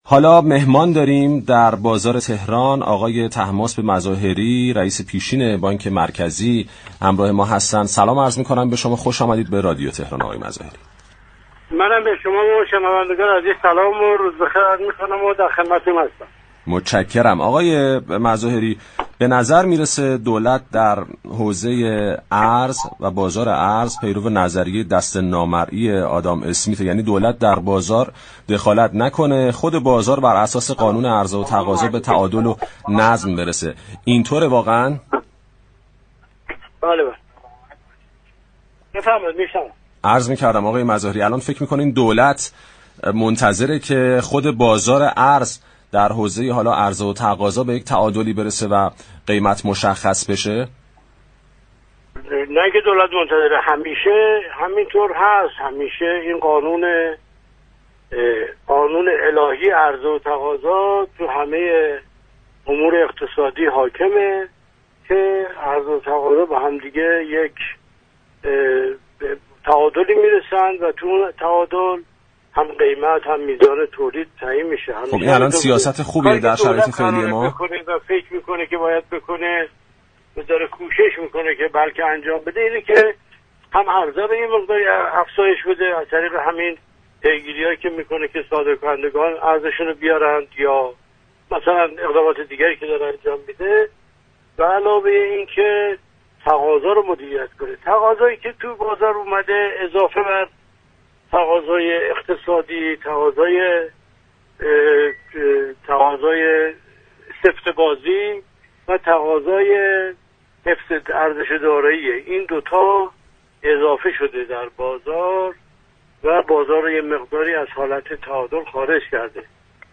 رئیس پیشین بانك مركزی در گفتگو با "بازار تهران" رادیو تهران و با اشاره به اینكه بر اساس عرضه و تقاضا به تعادل می رسد اظهار داشت: قانون عرضه و تقاضا همیشه بر تمام امور اقتصادی حاكم است و كاری كه دولت ملزم بر انجام آن است، افزایش عرضه و مدیریت تقاضا است.